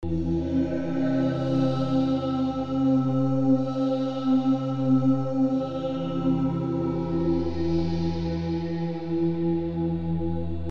描述：果味循环...
标签： 90 bpm Folk Loops Pad Loops 1.79 MB wav Key : Unknown
声道立体声